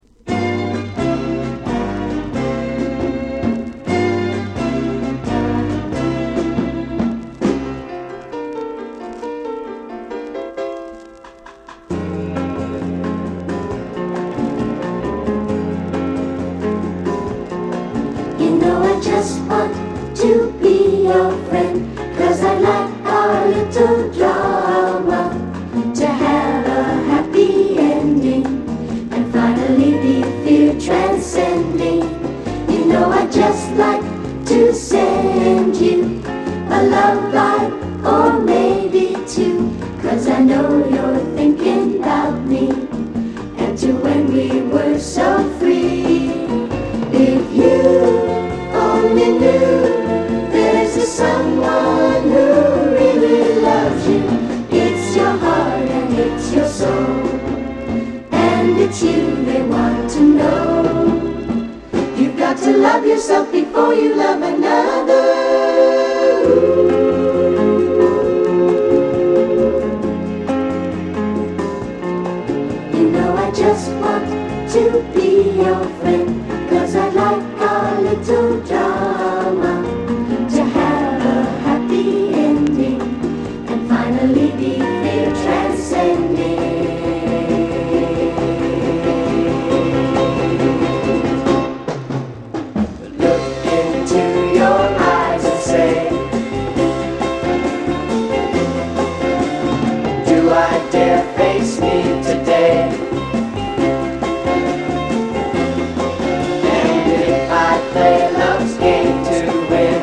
KIDS SOFT ROCKコーラスの大傑作！大人気キッズソウル